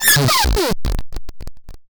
AlienTransmission2.wav